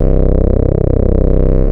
OSCAR C1 5.wav